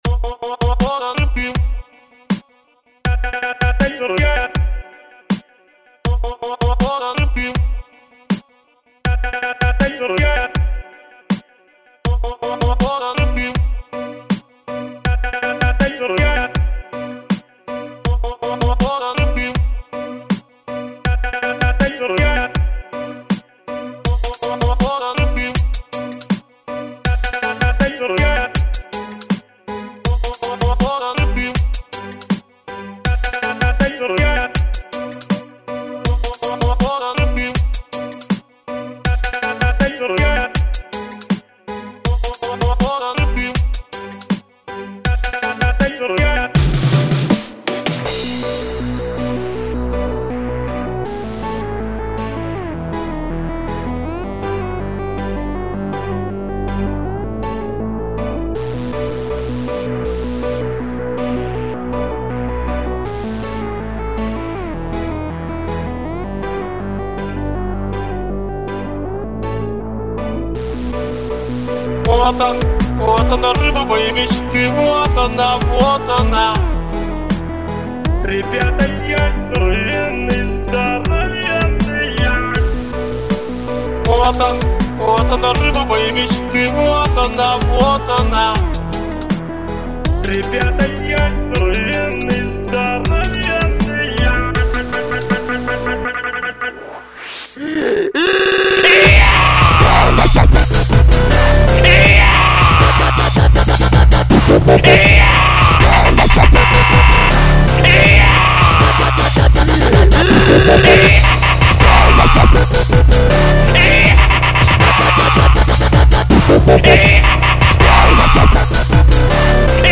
Список файлов рубрики DUB!!!!